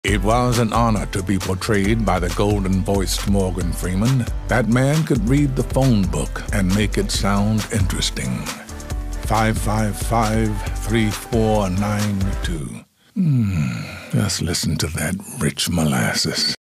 Is this why we love the dulcet tones of Morgan Freeman – simply because his voice is so deep, and therefore authoritative?
morgan-freeman-reading-the-phone-book.mp3